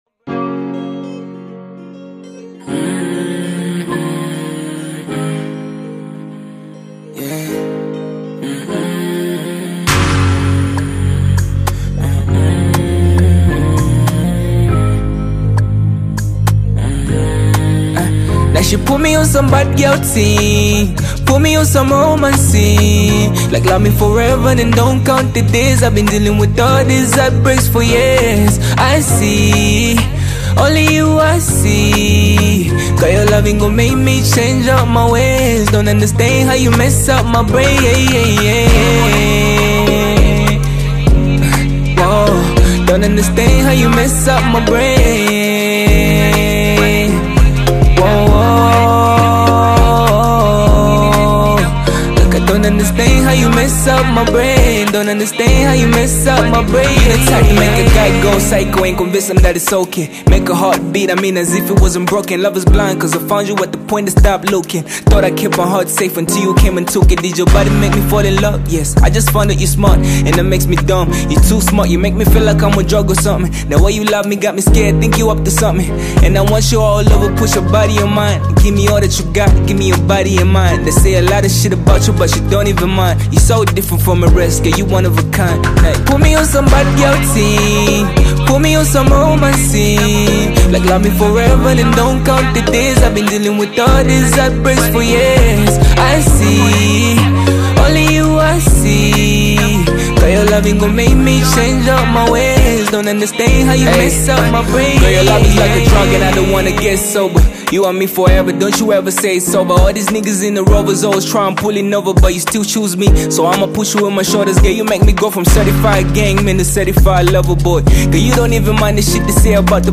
reflective and introspective track